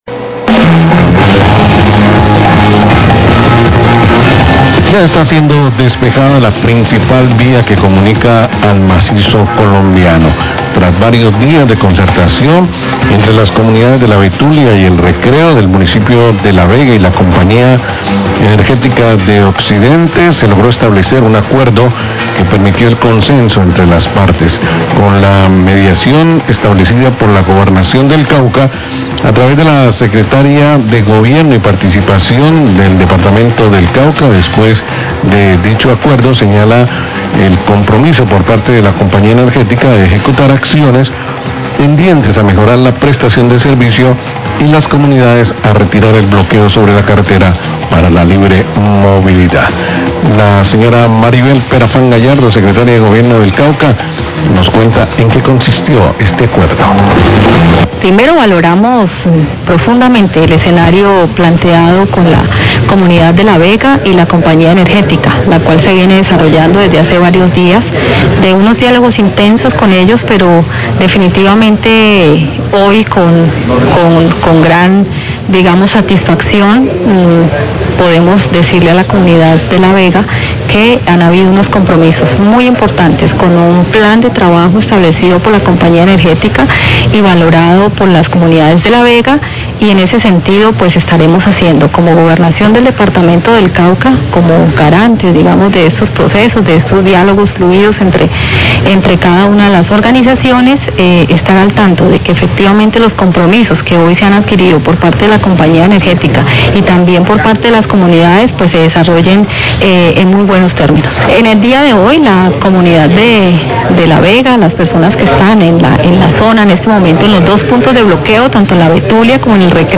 Radio
Se logró establecer un acuerdo entre las comunidades de La Vega y la Compañía Energética. La CEO se compromete a ejecutar acciones tendientes a mejorar el servicio de energía y las comunidades a retirar el bloqueo sobre la via al Macizo Colombiano. Declaraciones de la Secretaría de Gobierno del Cauca, Maribel Perafán.